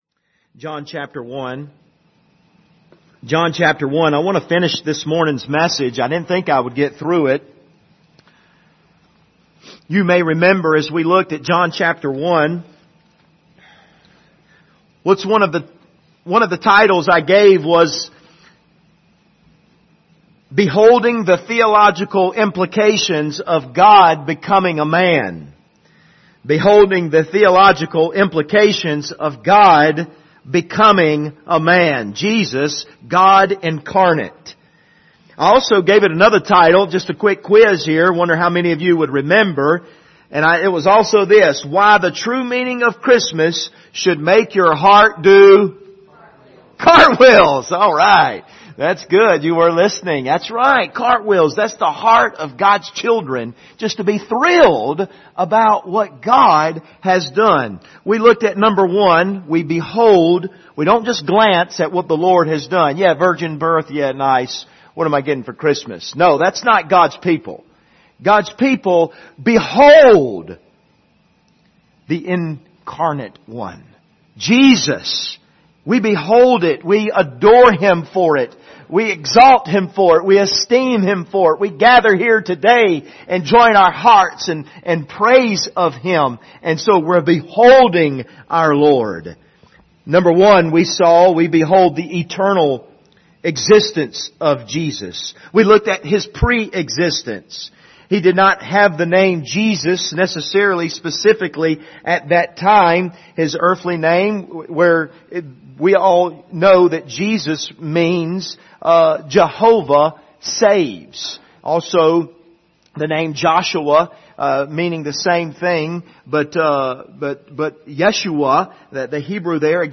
John 1:1-3,14,18 Service Type: Sunday Evening « Jesus